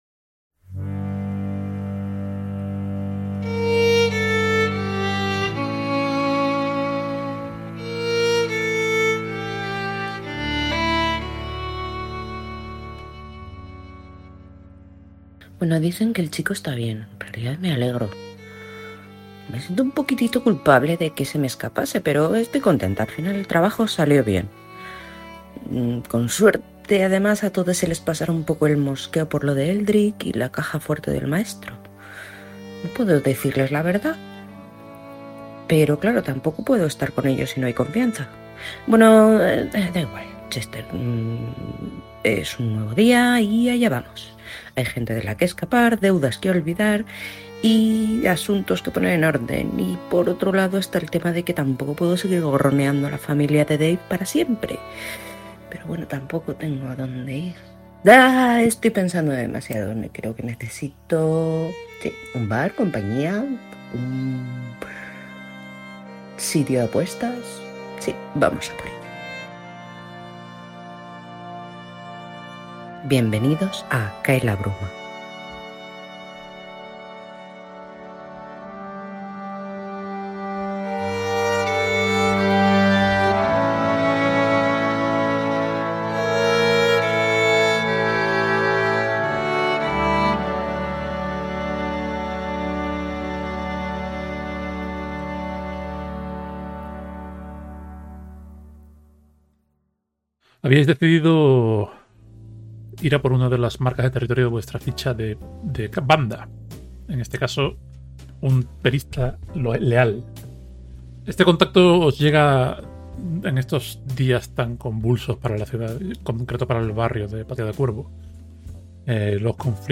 Cae la Bruma es una campaña de rol que se publica en formato podcast en Pasillo 8 y en formato stream en Éxito Crítico.